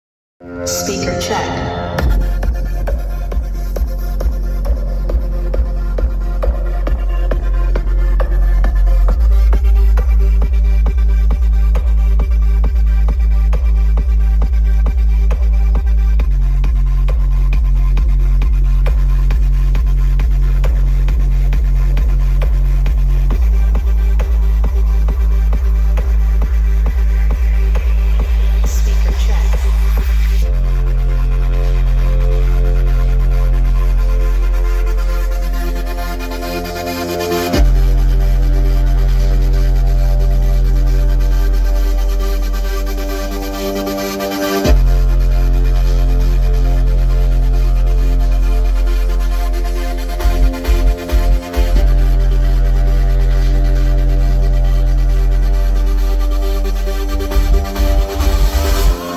Battle Remix
EXTREMELY POWERFUL SPEAKER TEST BASS MUSIC